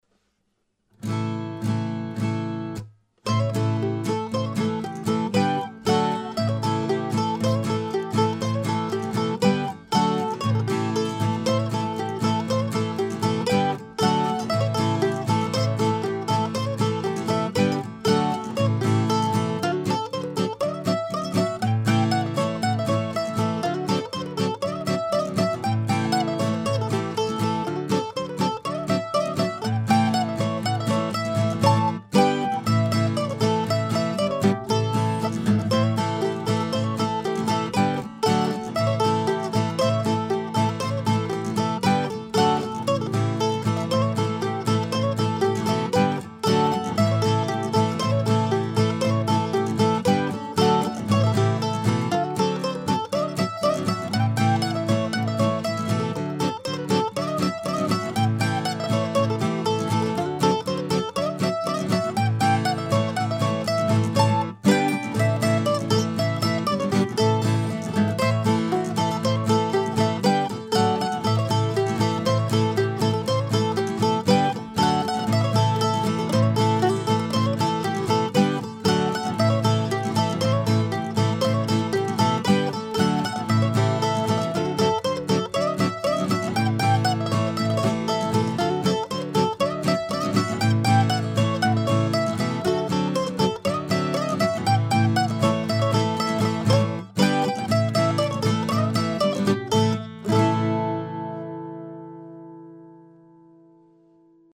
Here's a simple blues in fiddle tune form, captured somewhere not far from the Yazoo City train stop.